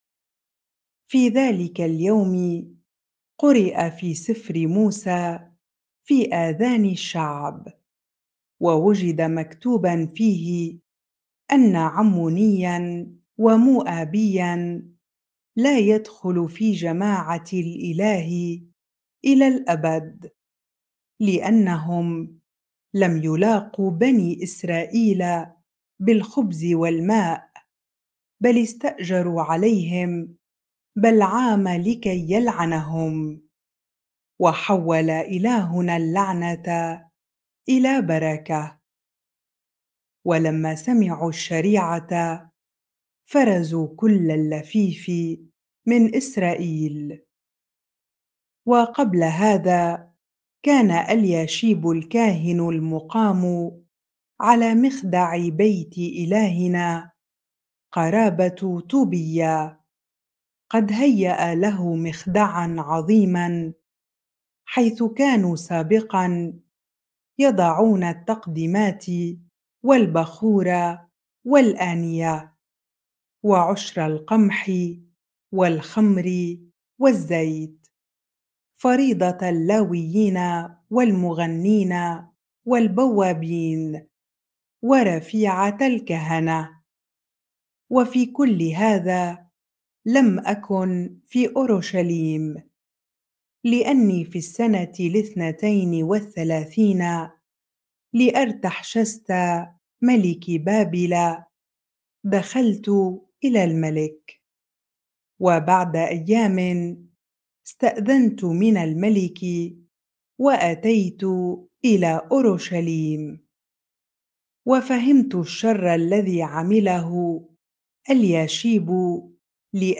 bible-reading-Nehemiah 13 ar